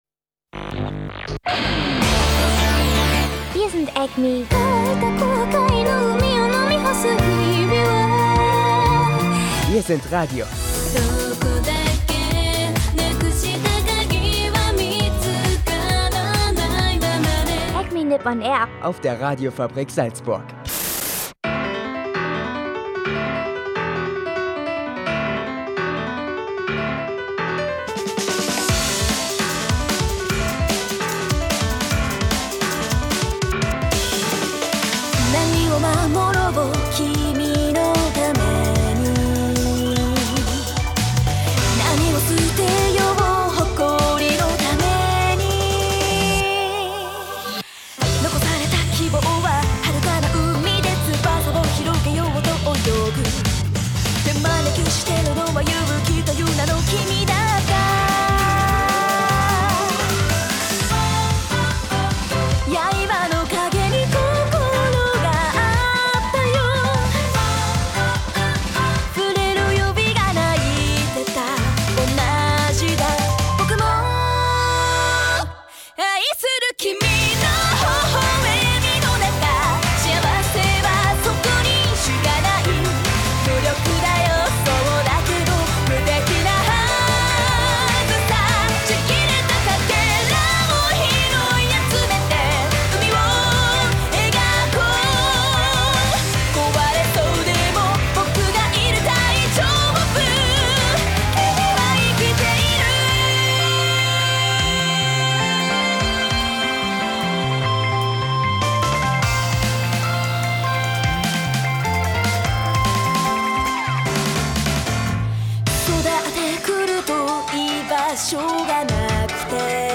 Also viel zu hören und dazu Anime-Musik und Wetter und was alles zur Sendung dazugehört.